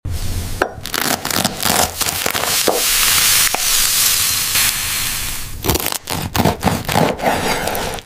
A flaky croissant and a juicy steak have a crisp, glass-like exterior that shatters when cut, only to reveal a shockingly realistic and soft interior. This surreal animation combines the best food visuals with impossible, brain-scratching sounds.